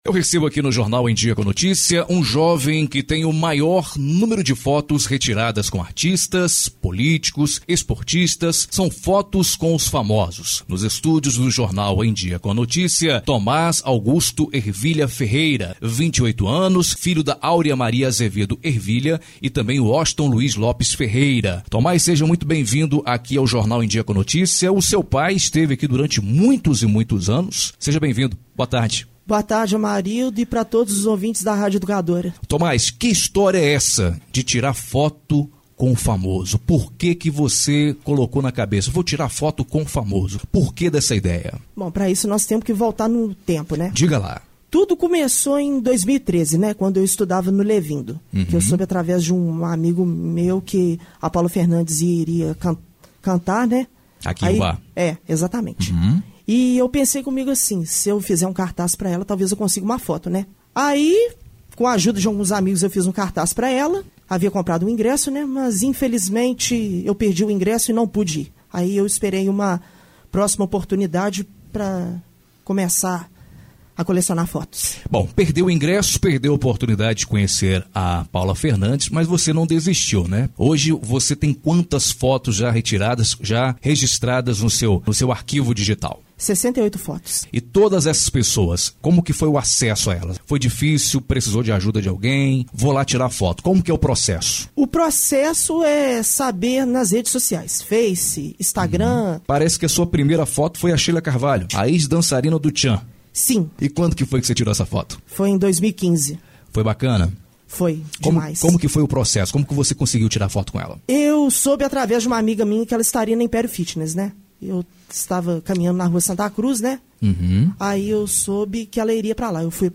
Entrevista exibida na Rádio Educadora AM/FM